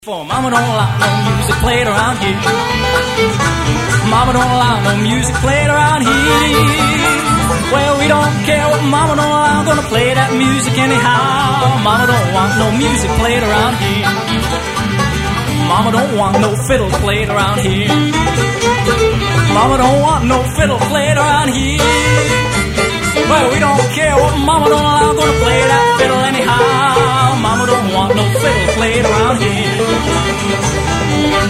Folk Song Lyrics